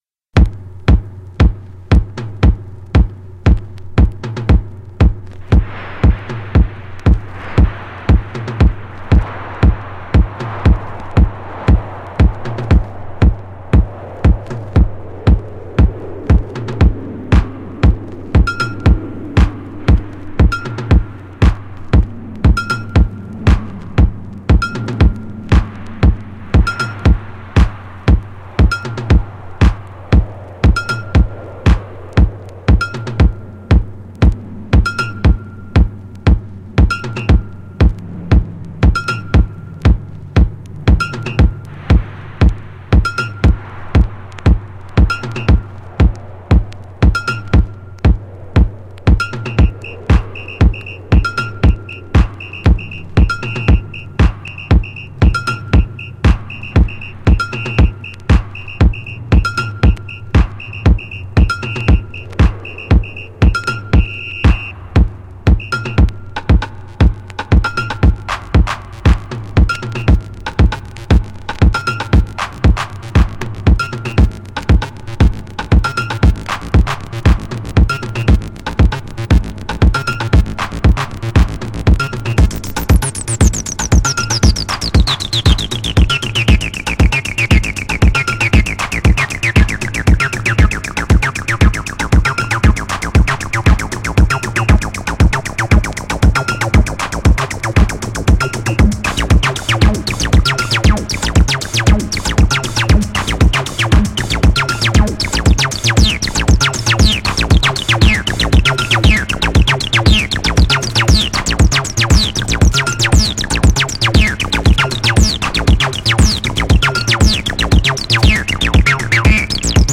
ハウス
ハウス・ミュージックにアシッド・サウンドを取り入れた、アシッド・ハウスの元祖とされる歴史的な一曲です。